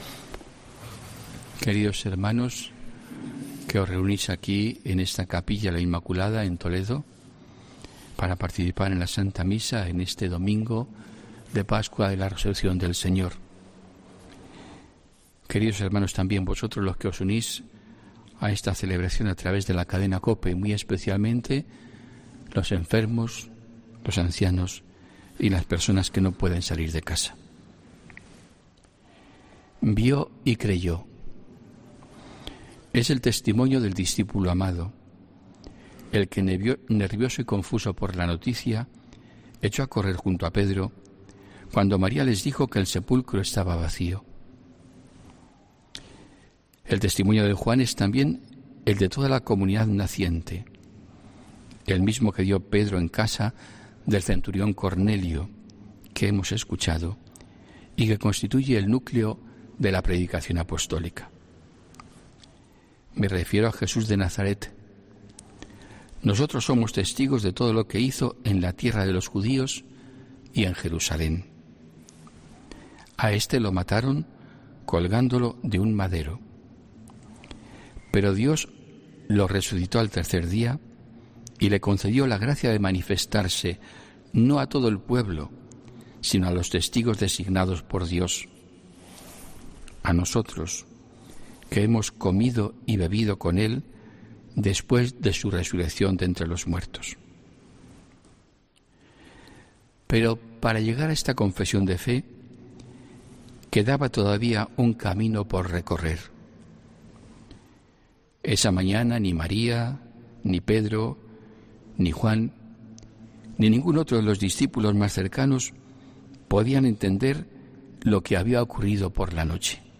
HOMILÍA 4 ABRIL 2021